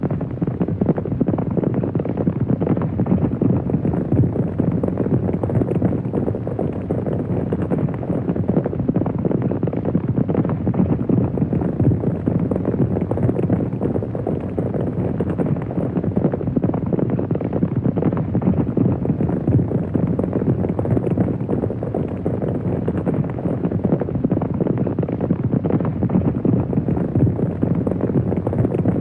Horses galloping 2 ringtone free download
Animals sounds